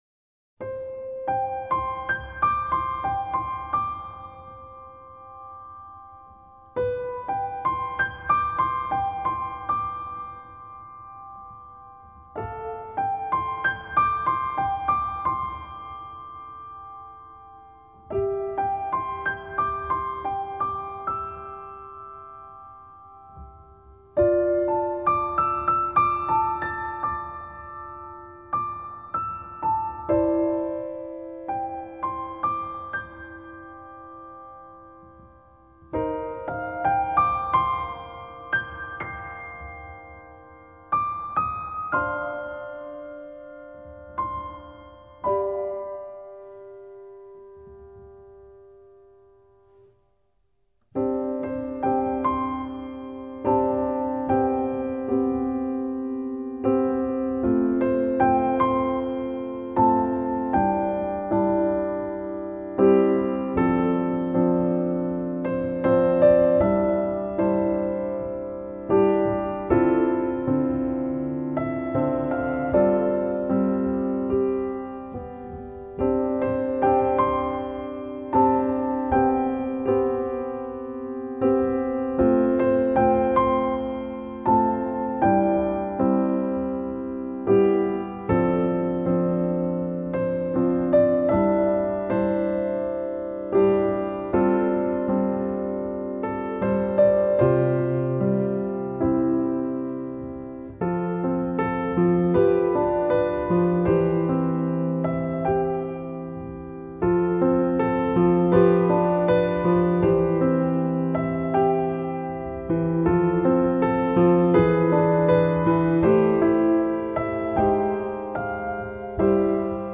音乐风格：钢琴 New Age